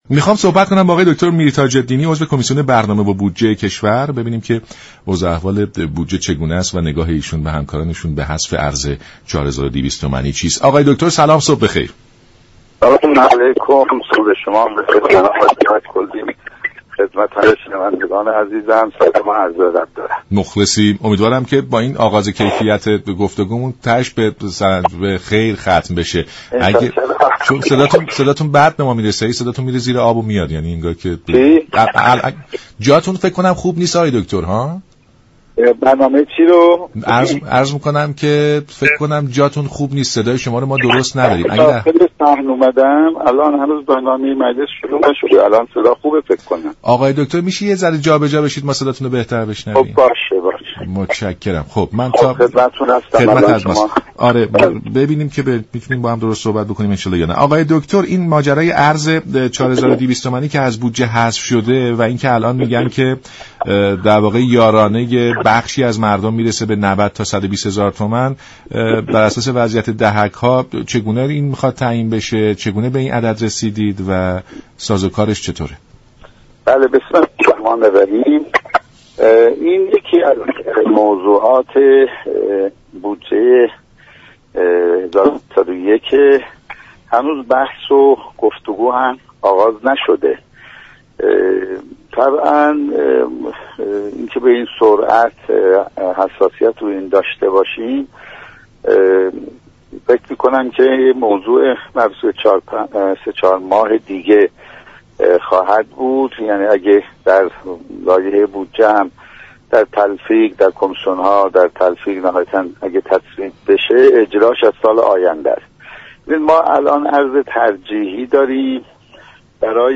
به گزارش شبكه رادیویی ایران، میر تاج الدینی عضو كمیسیون برنامه و بودجه مجلس در برنامه «سلام صبح بخیر» رادیو ایران درباره حذف ارز 4200 تومانی از بودجه سال 1401 گفت: این موضوع در زمره مهم ترین موضوعات بودجه سال 1401 است كه در صورت تصویب، اجرای آن از سال آینده آغاز خواهد شد.